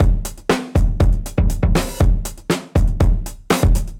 Index of /musicradar/dusty-funk-samples/Beats/120bpm